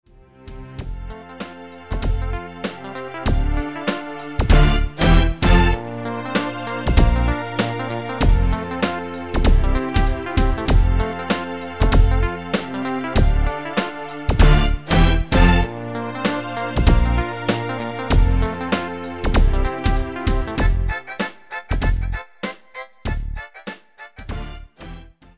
Demo/Koop midifile
U koopt een GM-Only midi-arrangement inclusief:
- Géén vocal harmony tracks